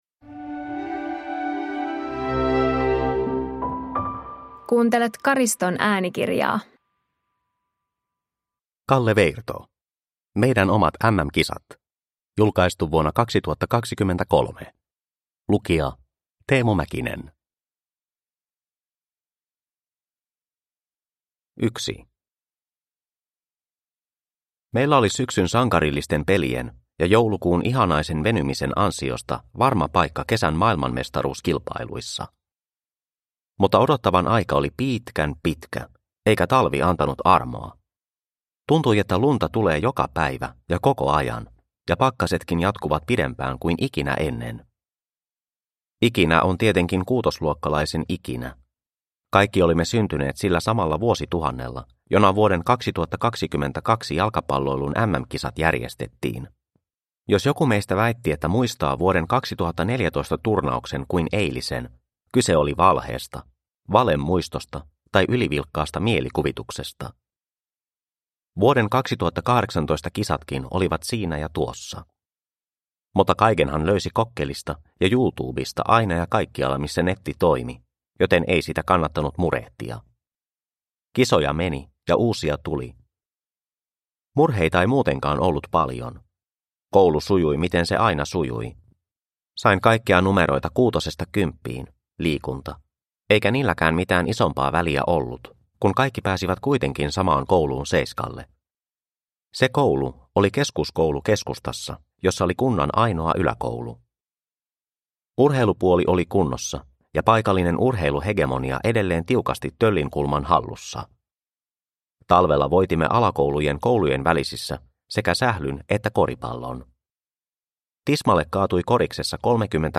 Produkttyp: Digitala böcker